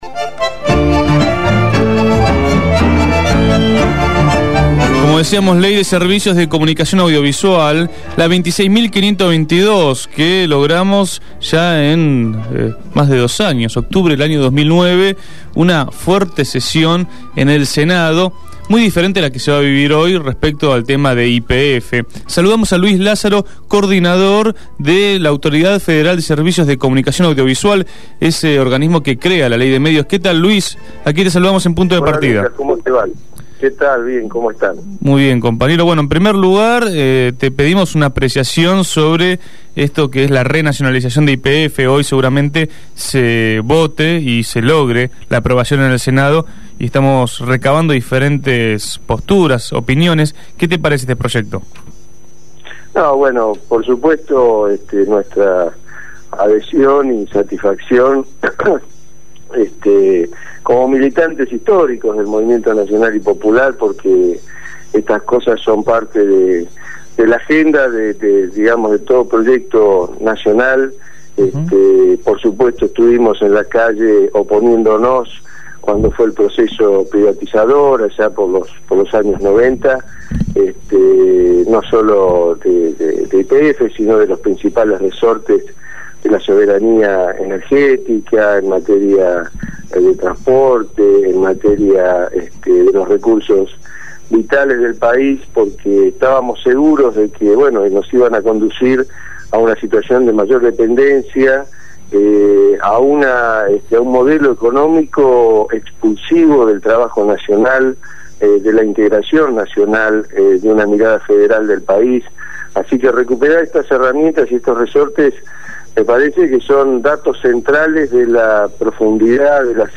Luis Lazzaro, Coordinador General de la Autoridad Federal de Servicios de Comunicación Audiovisual habló con Punto de Partida.